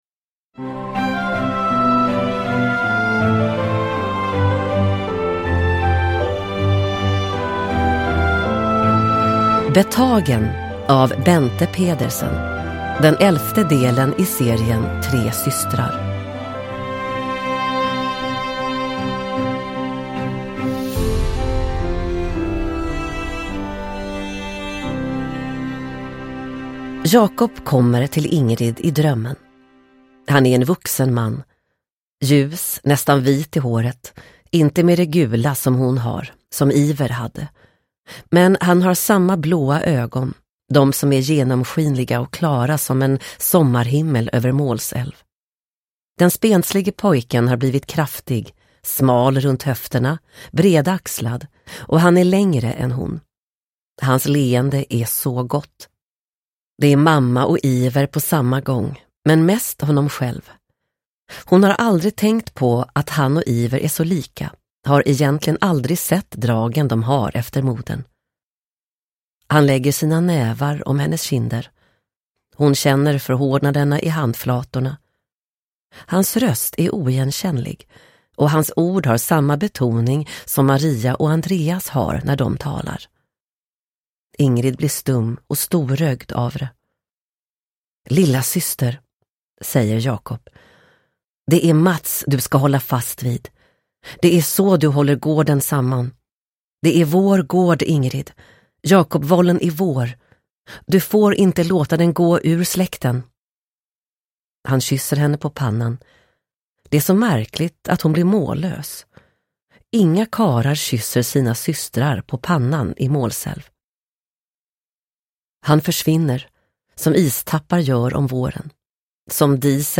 Betagen – Ljudbok – Laddas ner